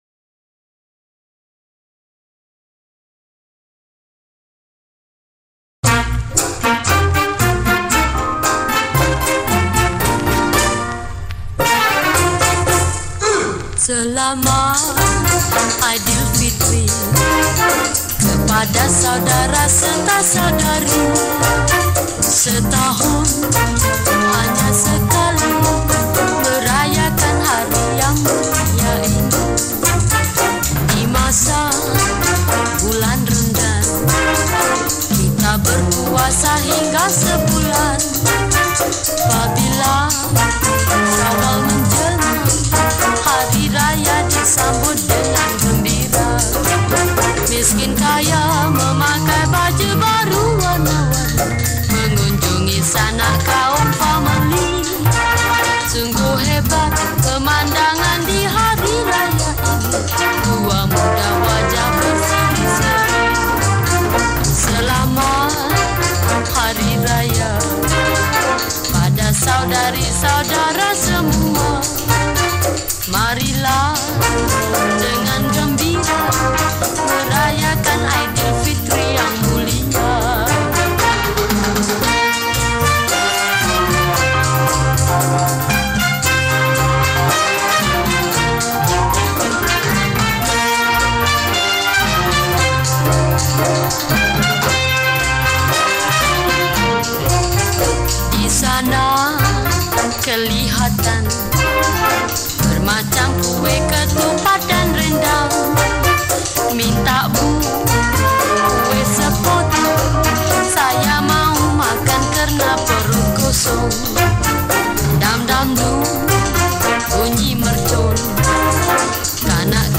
Solo Recorder